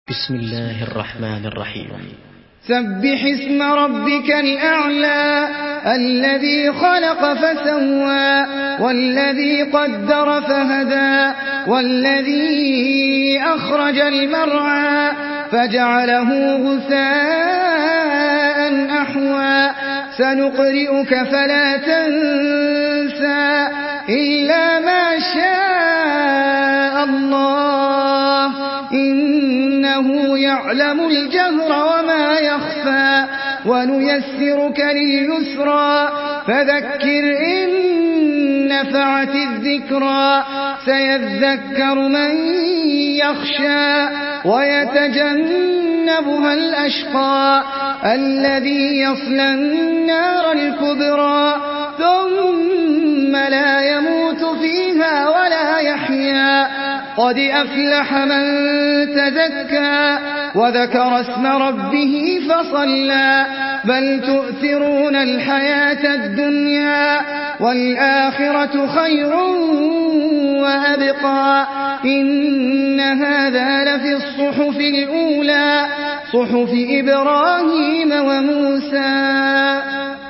تحميل سورة الأعلى بصوت أحمد العجمي
مرتل حفص عن عاصم